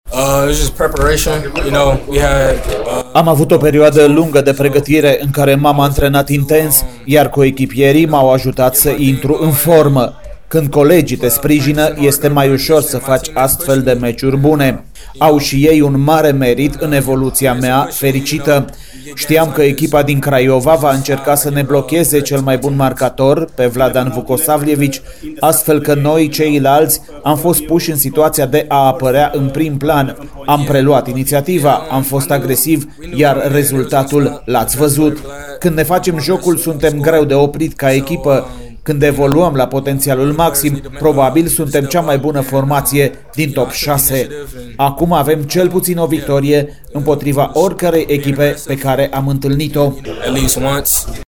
a vorbit, după meci, despre această victorie: